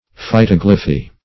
Search Result for " phytoglyphy" : The Collaborative International Dictionary of English v.0.48: Phytoglyphy \Phy*tog"ly*phy\, n. [Phyto- + Gr. gly`fein to engrave.]
phytoglyphy.mp3